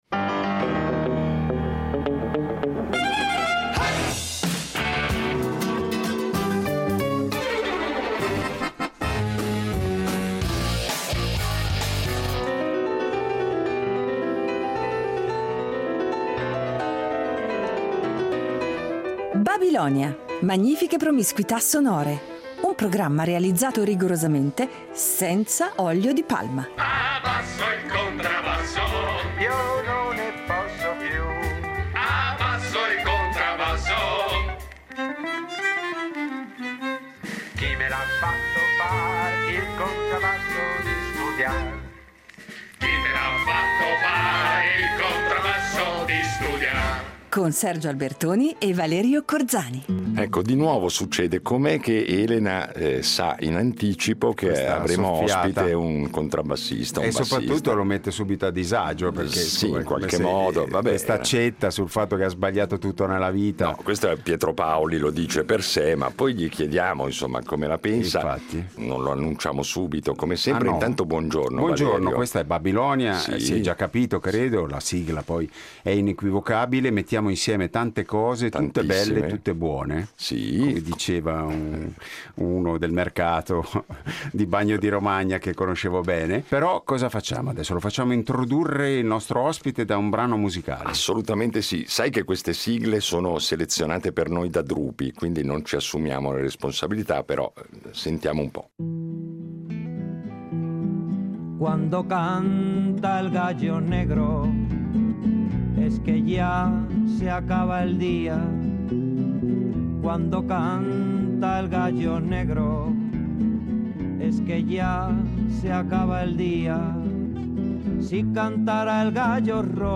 Il nostro ospite di oggi è una delle figure più sorprendenti, poliedriche e vitali della scena musicale italiana contemporanea.